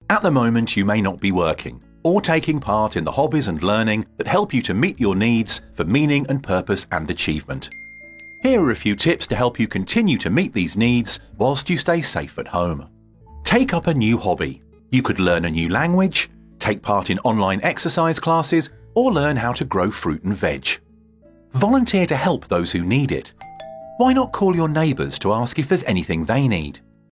Inglês (britânico)
E-learning
Microfone: SHURE SM7B